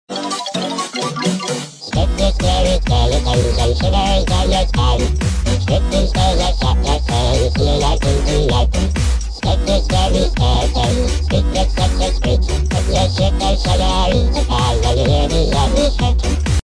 Spooky scary aliens